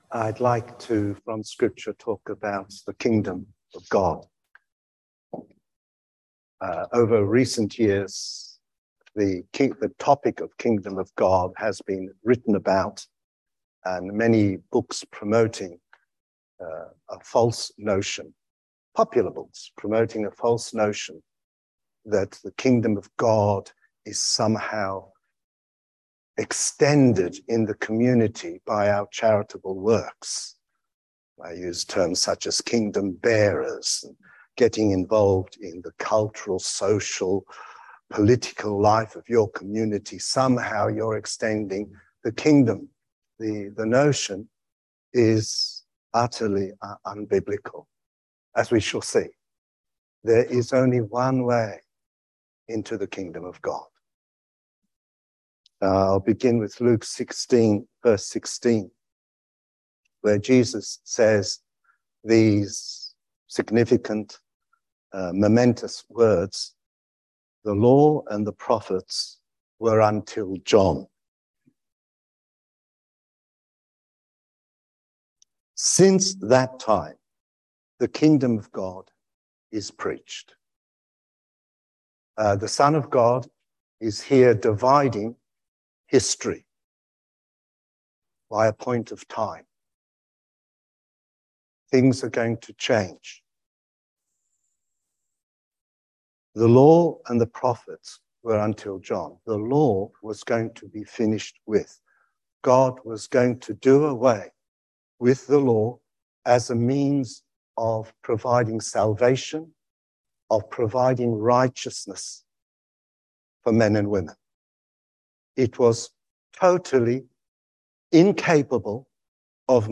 THE KINGDOM OF GOD You can also listen to a talk on this subject HERE THE KINGDOM OF GOD AND SOCIAL ACTION AUTHORITY IN THE CHURCH: WOMEN PASTORS?